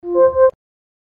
SFX – AMAZON ECHO – LOW ENERGY MODE ON
SFX-AMAZON-ECHO-LOW-ENERGY-MODE-ON.mp3